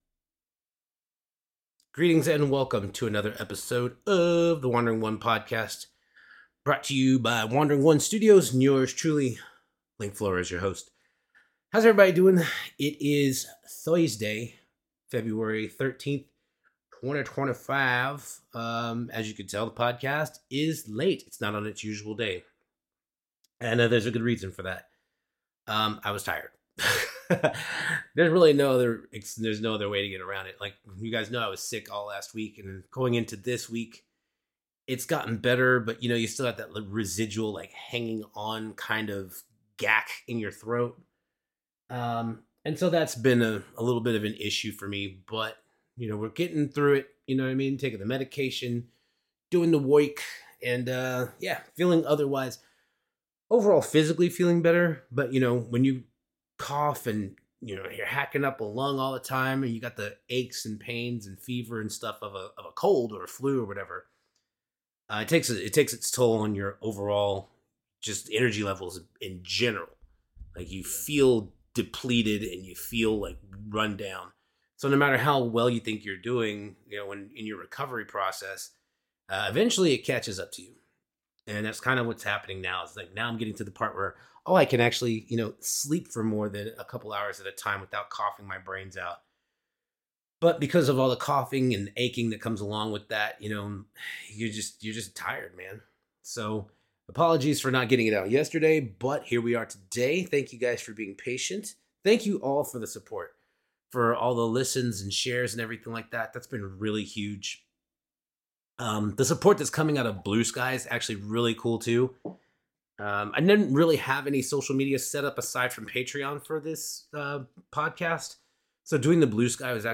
<<< WARNING: May contain adult language and thematic content.
>>> *** Only a couple of coughs, but the worst of it is at the end.